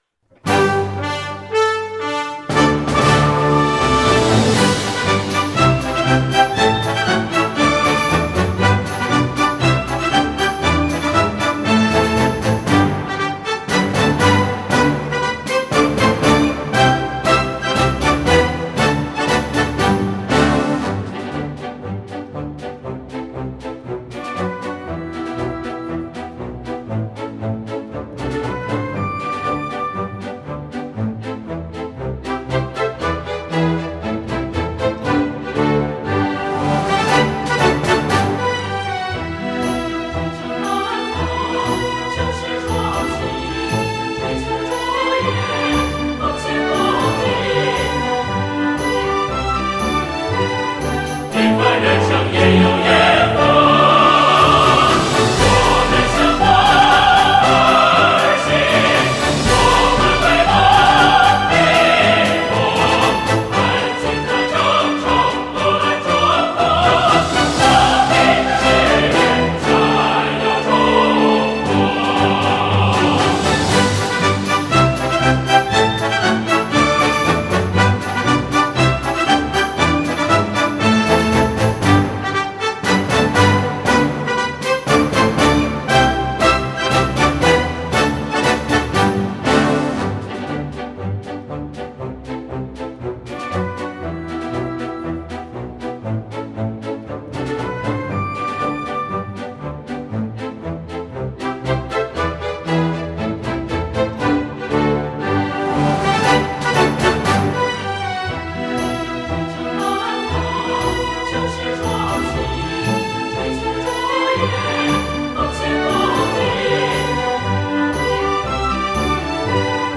总体以进行曲为曲调，气势雄壮、节奏铿锵、催人奋进
伴奏（合唱版）